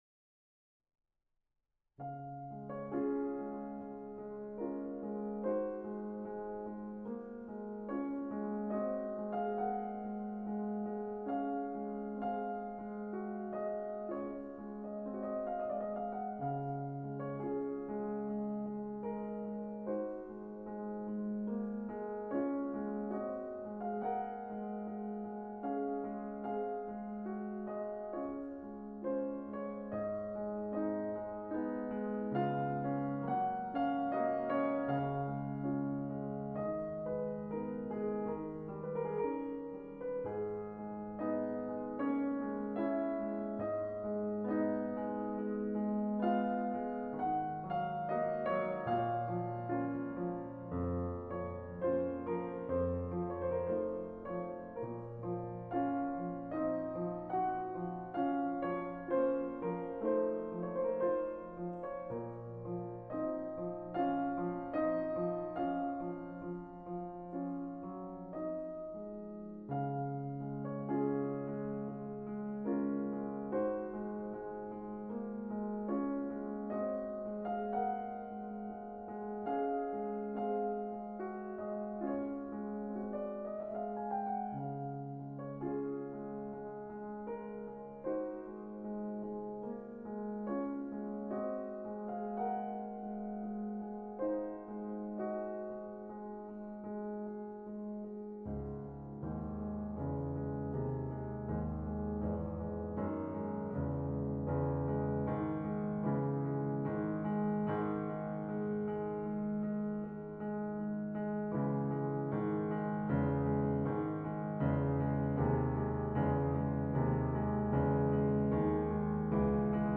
/ فیلم برچسب‌ها: Chopin Classical frederic chopin Music شوپن موسیقی اهنگ زیبا اهنگ دیدگاه‌ها (اولین دیدگاه را بنویسید) برای ارسال دیدگاه وارد شوید.
prelude_no._15_in_d-flat_major_op._28.mp3